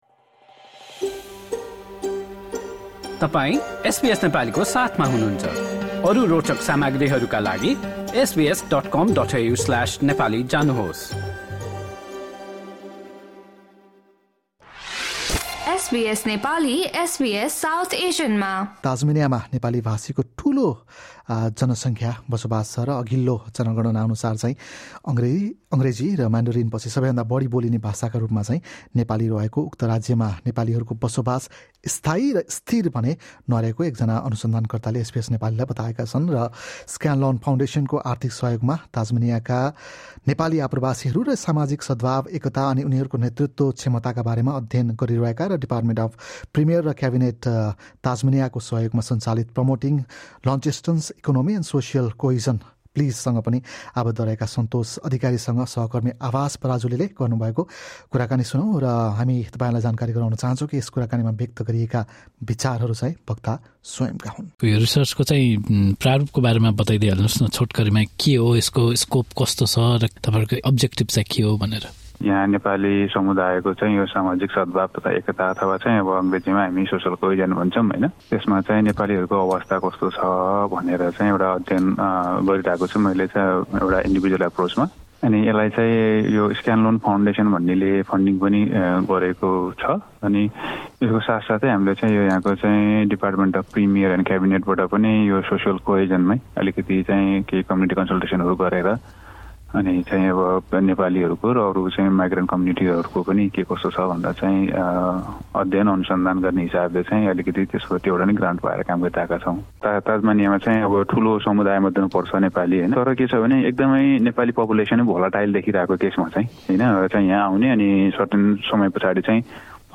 एसबीएस नेपालीले गरेको कुराकानी सुन्नुहोस्।